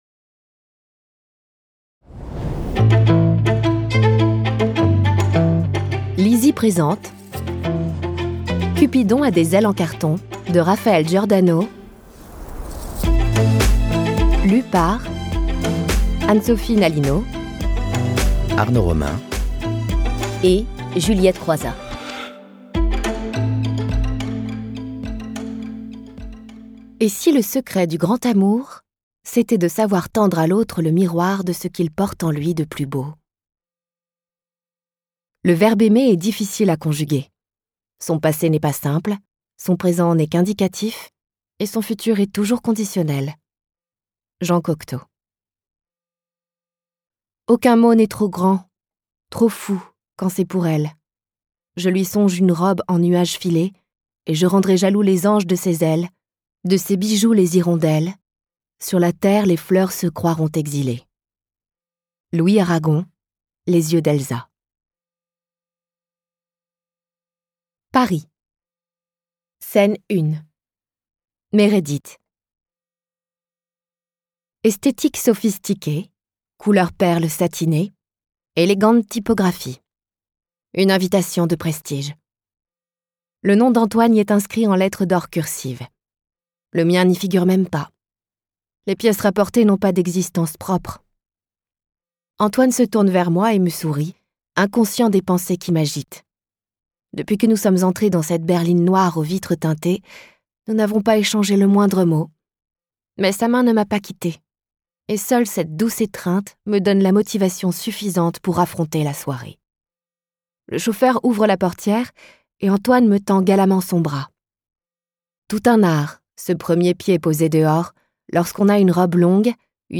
Click for an excerpt - Cupidon a des ailes en carton de Raphaëlle GIORDANO